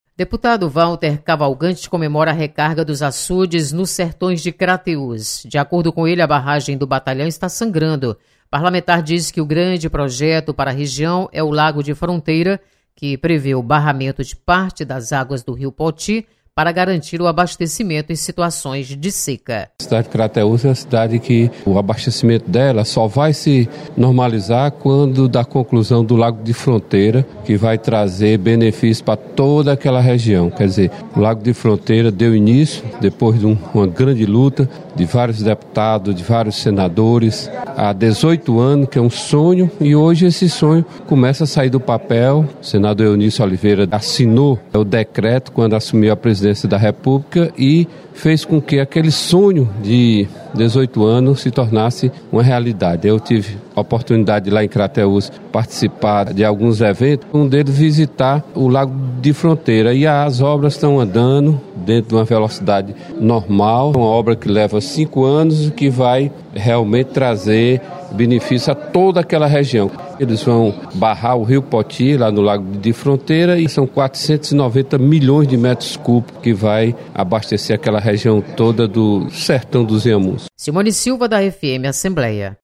Aporte de água nos açudes anima deputado Walter Cavalcante. Repórter